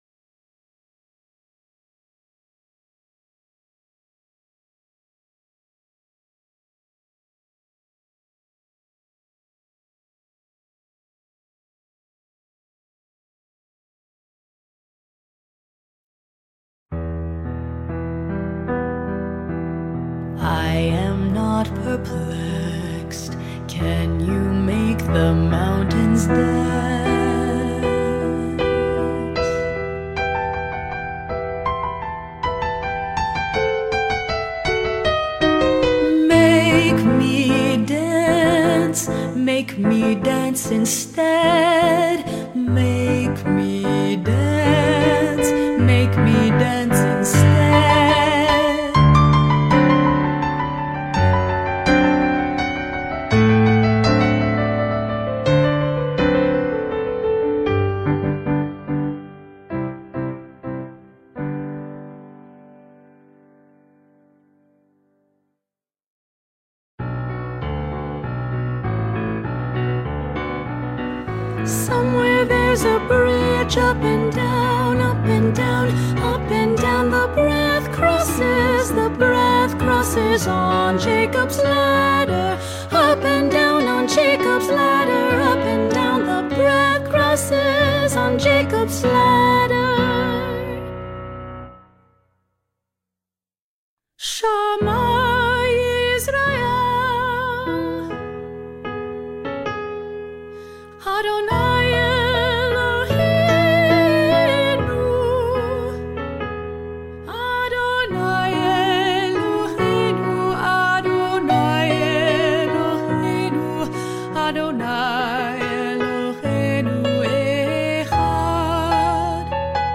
Ten Haiku Songs for Tenor and Piano
there will be a 17 second pause before the music starts
piano
lyric vocal tracks which accompany the piano